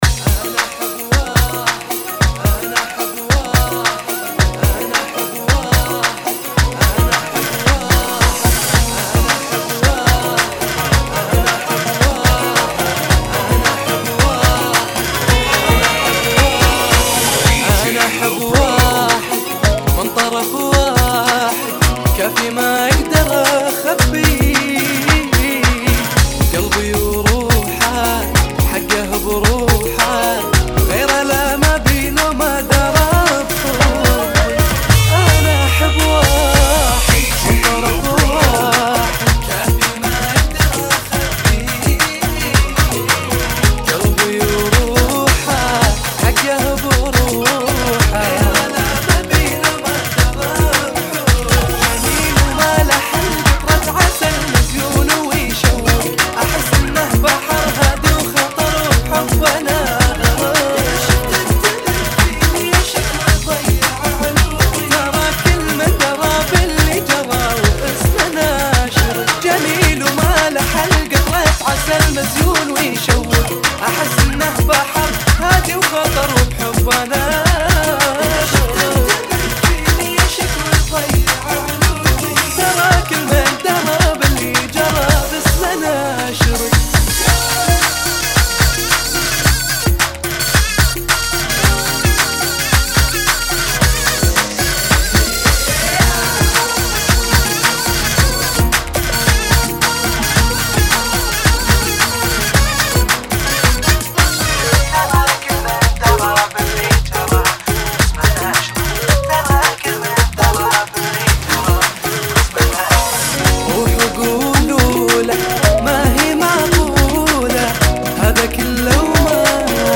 110 Bpm ] - Funky